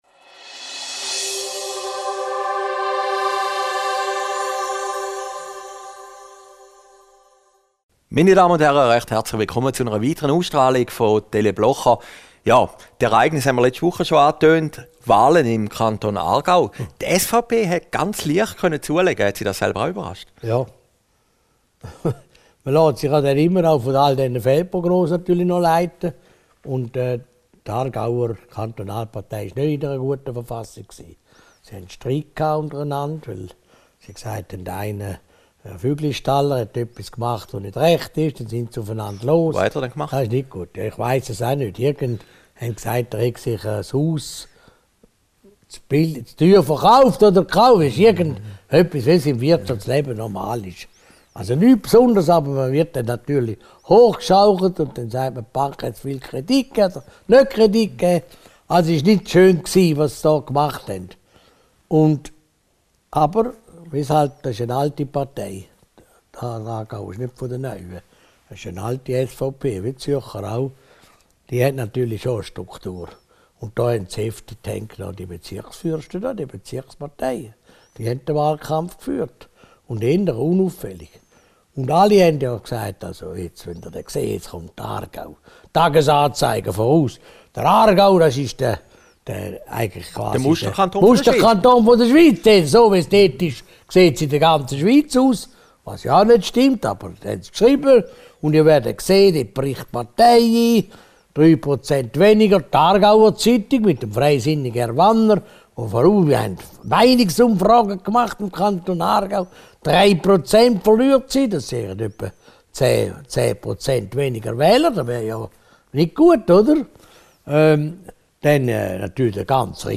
Video downloaden MP3 downloaden Christoph Blocher über Ueli Maurer, das „Hello-Desaster“ und die Freizügigkeitsinitiative Aufgezeichnet in Herrliberg, 25.